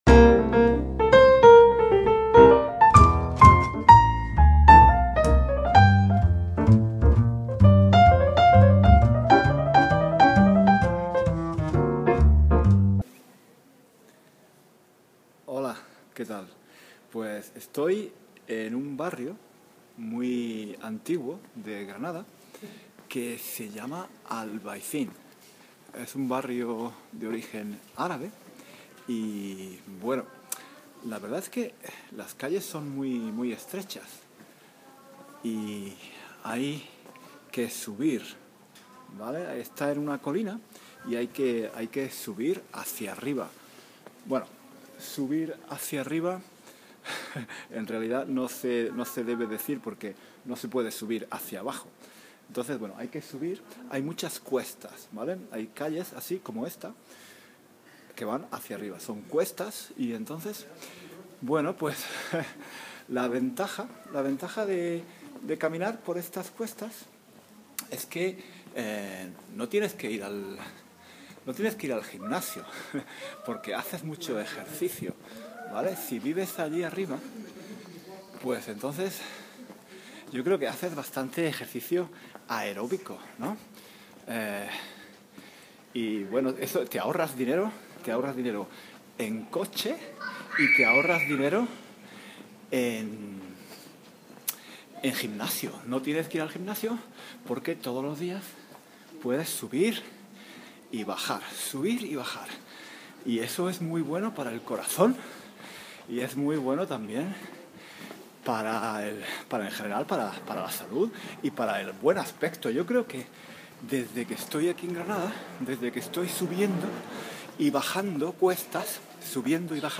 Hoy doy un paseo por mi barrio en Granada.